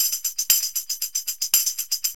TAMB LP 118.wav